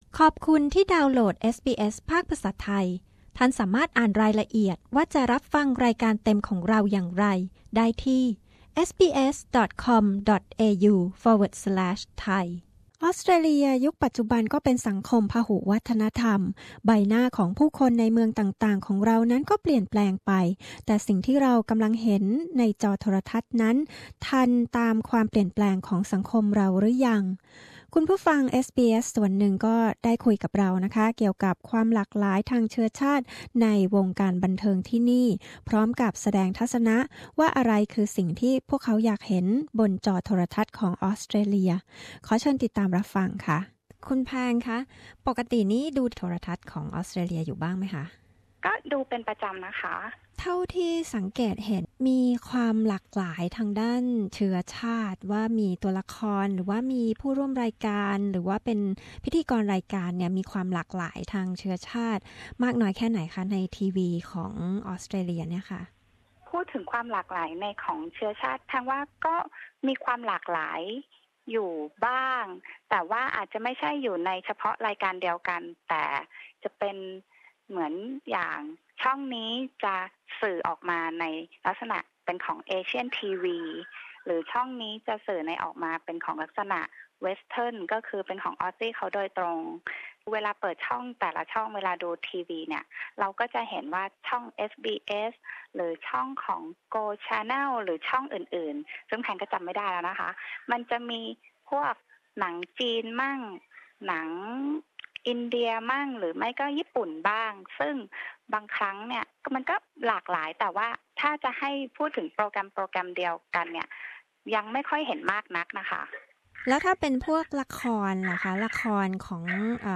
วงการบันเทิงในออสเตรเลียมีความหลากหลายทางเชื้อชาติแค่ไหน และควรพัฒนาไปอย่างไร ท่านผู้ฟังคุยกับเอสบีเอส ไทยเกี่ยวกับประเด็นนี้